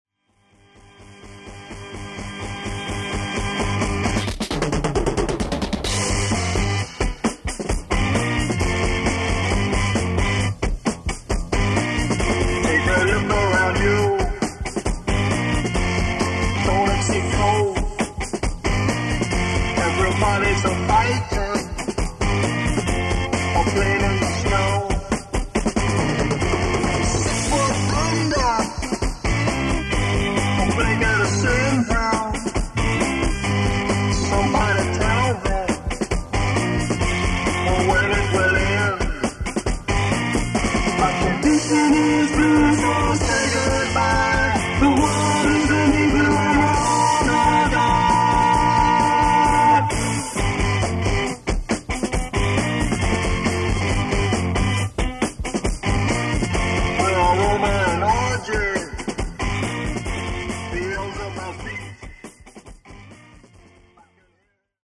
DISC ONE      Studio Tracks
piano, organ, keyboard bass, lead vocals,
guitar & backing vocals
drums